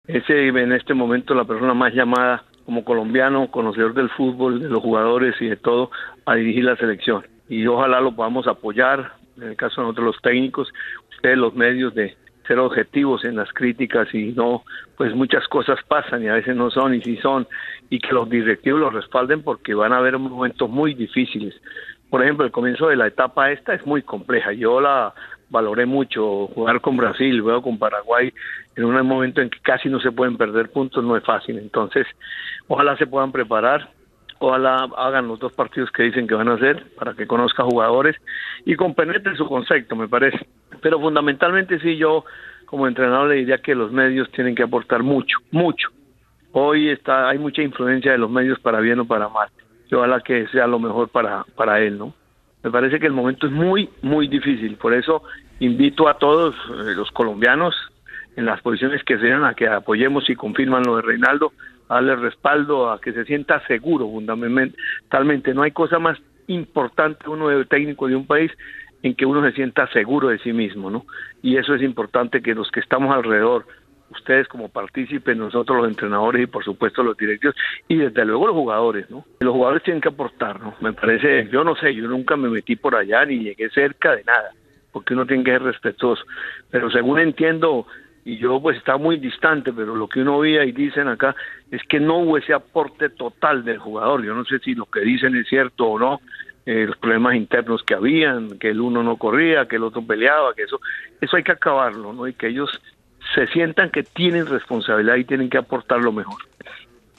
Jorge Luis Pinto, ex seleccionador de Colombia, en diálogo con Caracol Radio